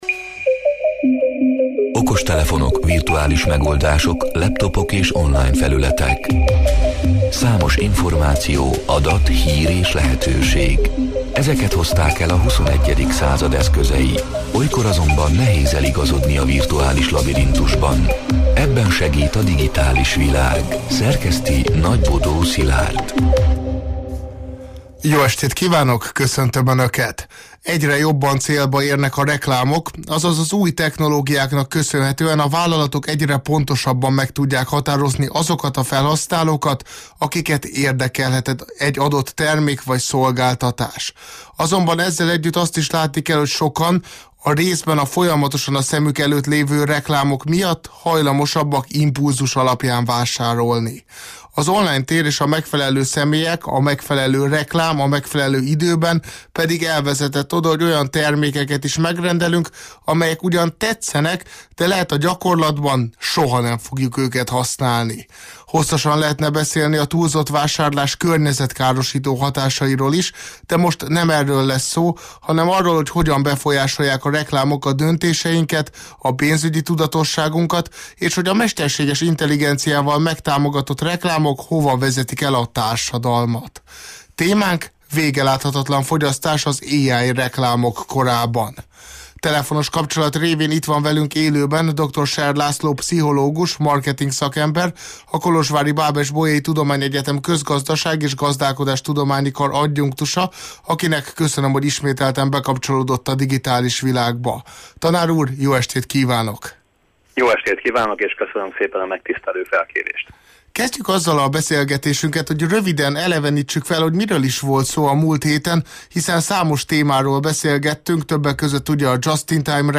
A Marosvásárhelyi Rádió Digitális Világ (elhangzott: 2025. december másodikán, kedden este nyolc órától élőben) c. műsorának hanganyga: